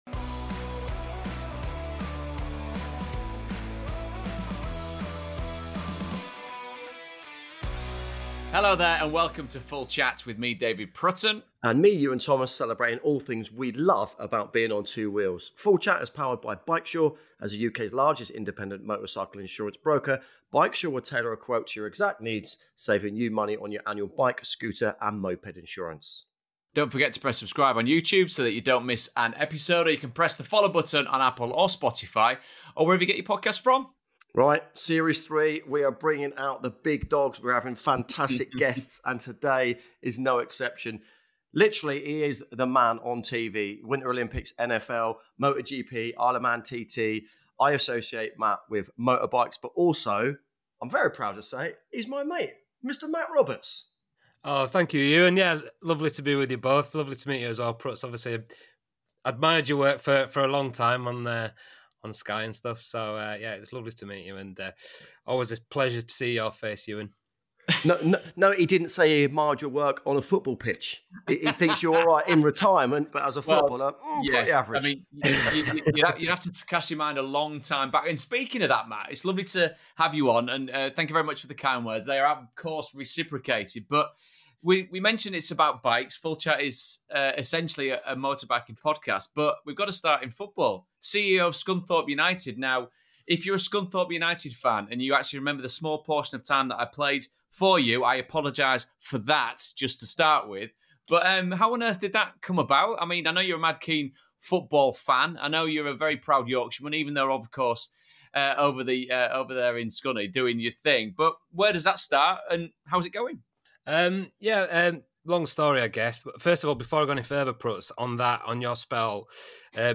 sit down for a good old fashioned chinwag